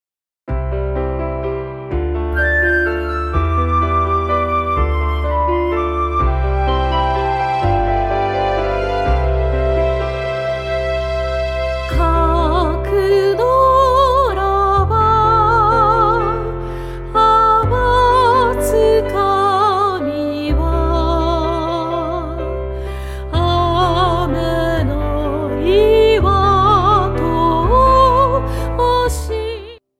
８つの曲を通して、澄んだ歌声と優しい響きが、心に静けさと力を届けます。